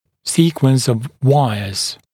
[‘siːkwəns əv ‘waɪəz][‘си:куэнс ов ‘уайэз]последовательность дуг